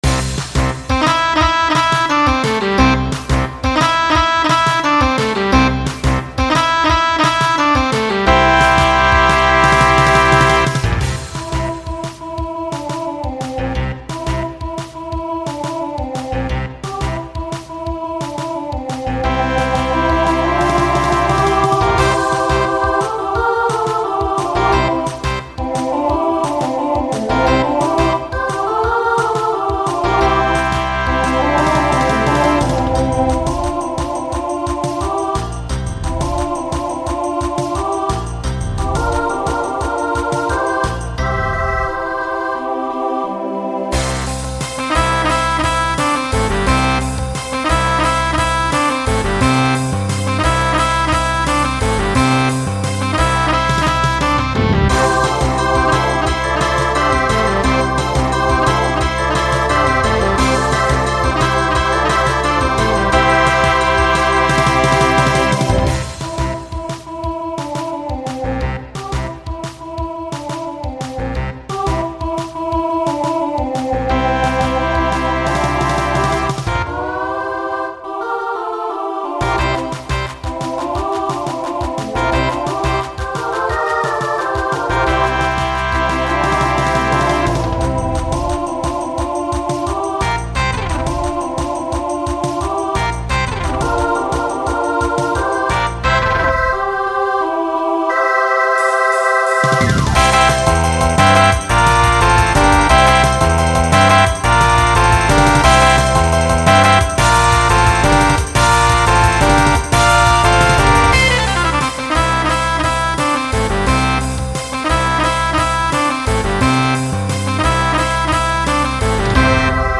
Voicing SSA Instrumental combo Genre Pop/Dance